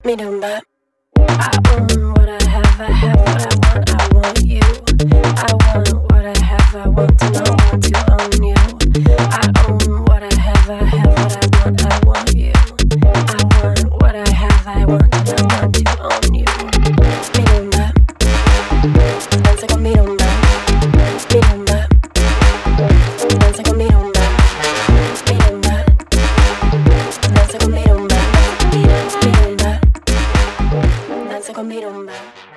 • Качество: 320, Stereo
заводные
Electronic
басы
house